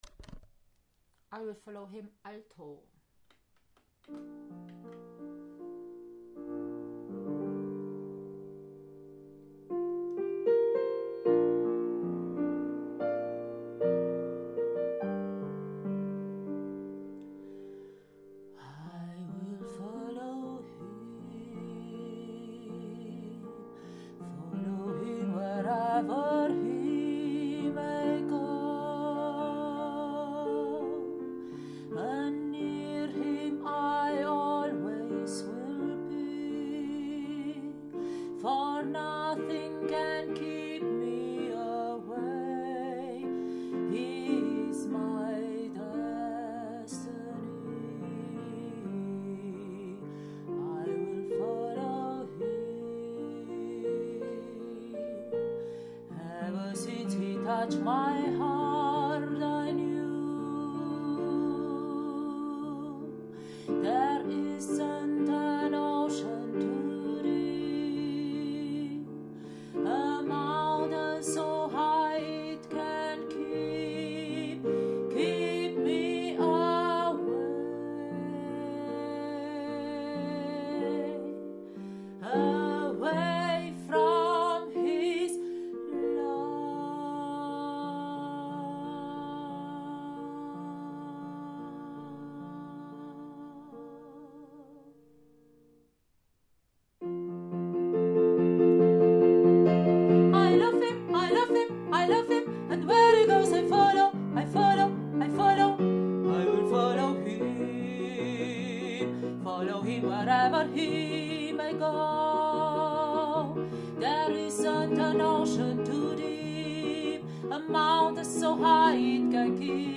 I will follow him – Alto
I-will-follow-him-Alto.mp3